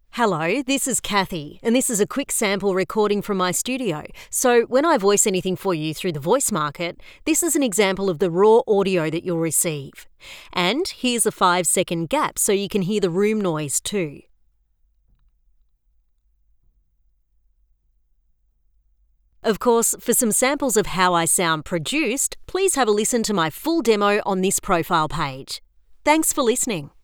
• Studio Sound Check
• Natural, warm,
• Bright
• Booth: Purpose built in home office
• Mic: Rode NT1-A